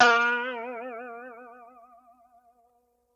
Boing (2).wav